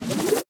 Minecraft Version Minecraft Version 1.21.5 Latest Release | Latest Snapshot 1.21.5 / assets / minecraft / sounds / mob / breeze / charge1.ogg Compare With Compare With Latest Release | Latest Snapshot
charge1.ogg